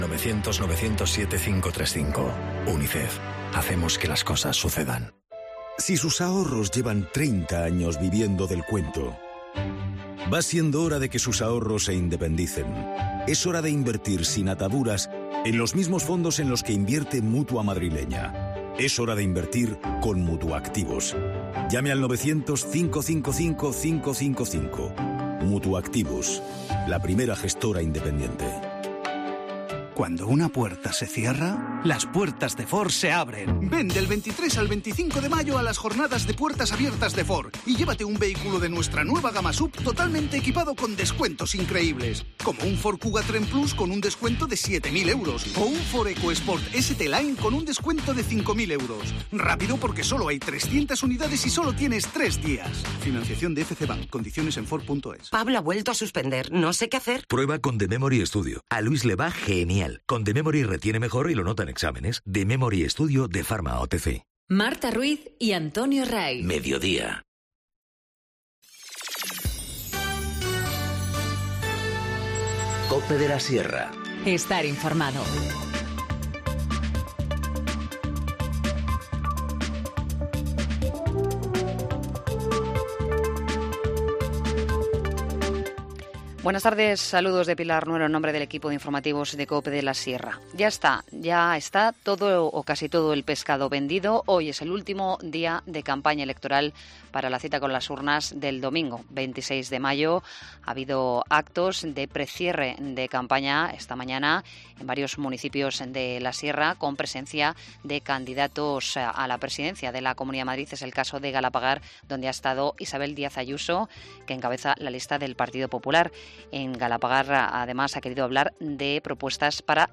Informativo Mediodía 24 mayo 14:20h